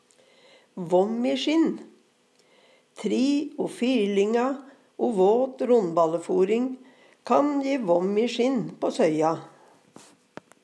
vomisjinn - Numedalsmål (en-US)